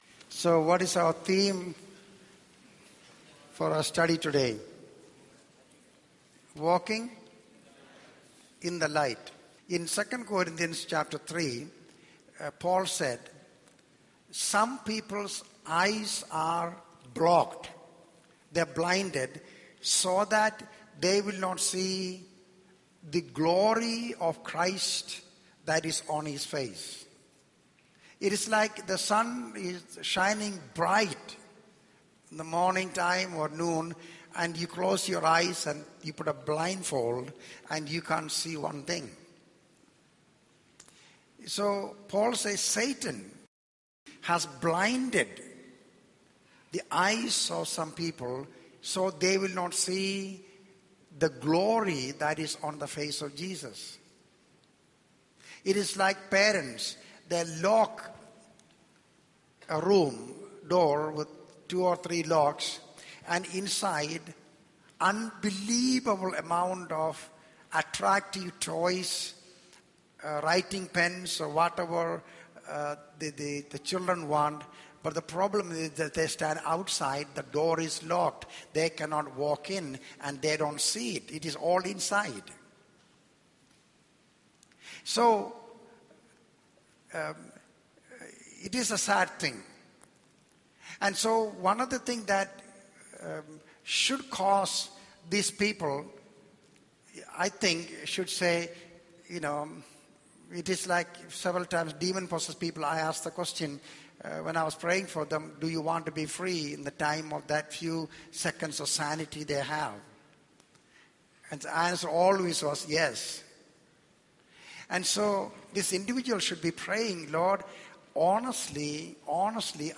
In this sermon, the speaker discusses the struggle of making decisions when faced with conflicting choices.